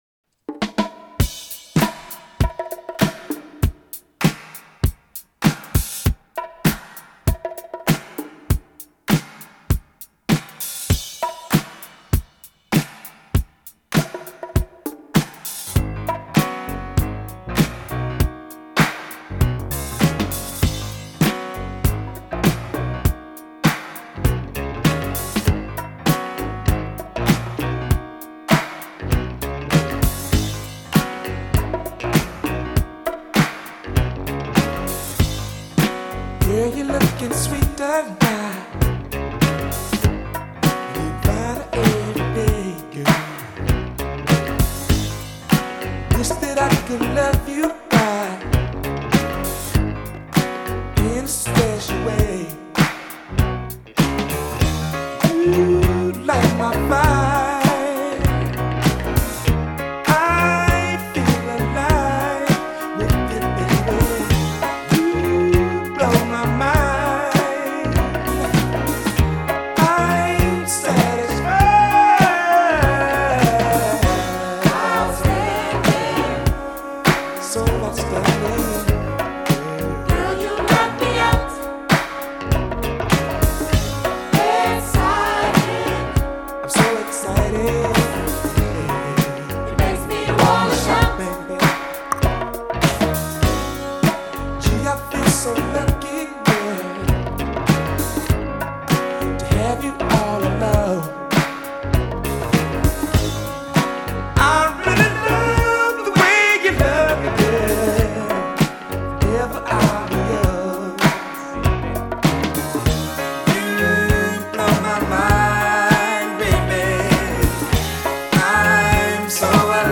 12″ mix